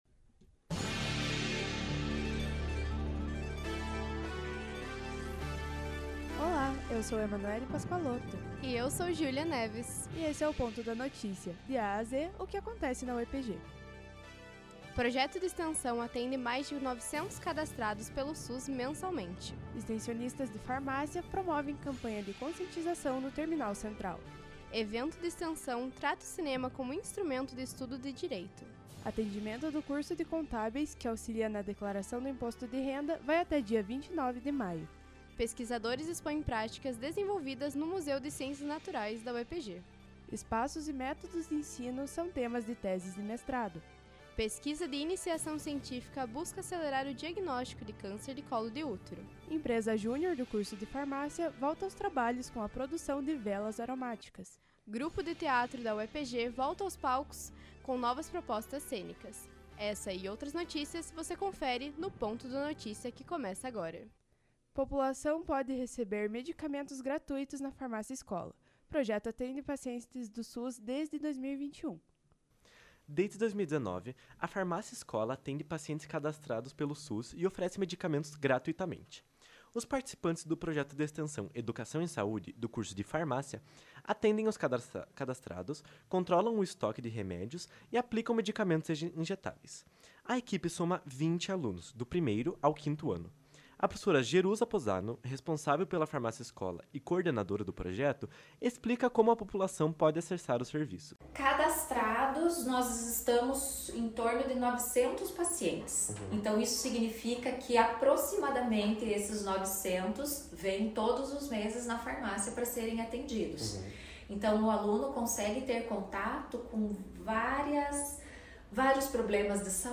Radiojornal-142.mp3